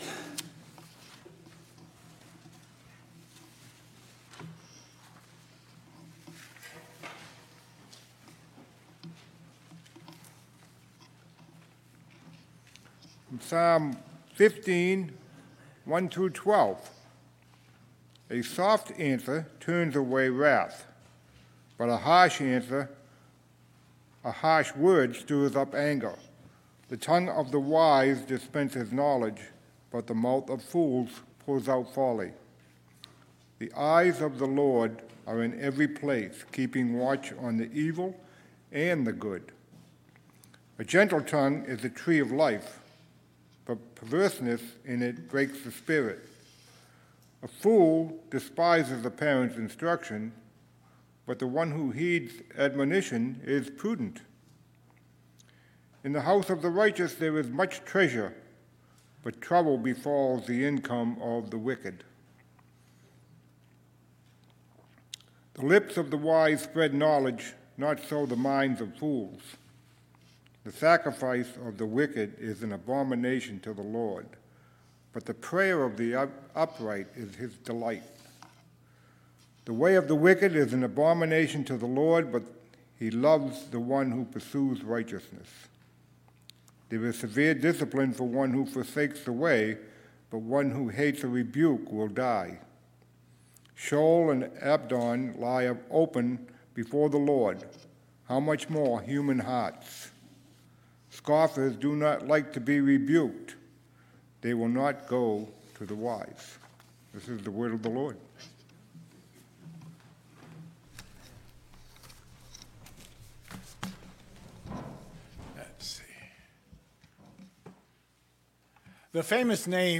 Scripture-Reading-and-Sermon-Oct.-9-2022.mp3